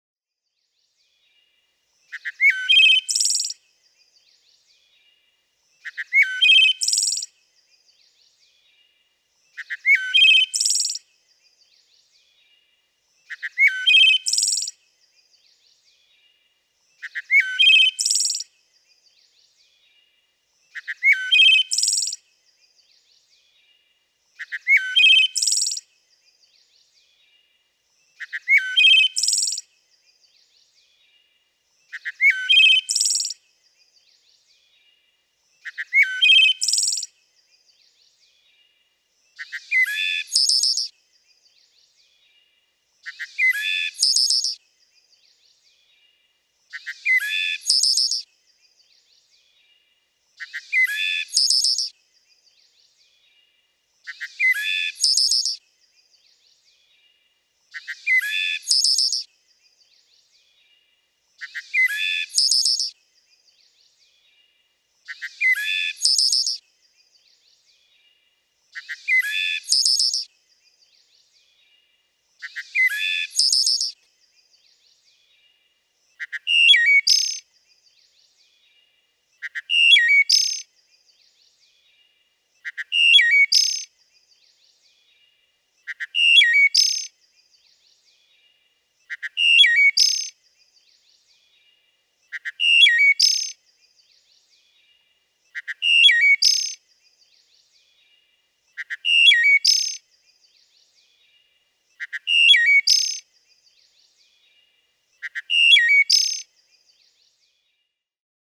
Here are three different songs, each repeated 10 times, for a total of 30 songs. No wood thrush would ever sing like this, of course; I concocted this recording by rearranging and repeating songs in my editing software.
443_Wood_Thrush.mp3